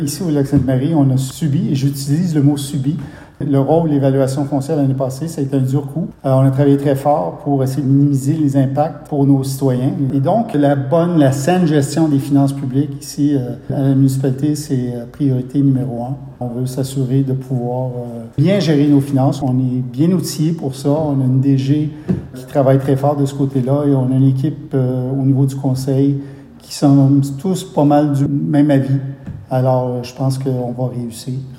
Monsieur Beaudoin a affirmé que la bonne gestion des finances publiques de la Municipalité sera sa priorité numéro 1 pendant son mandat :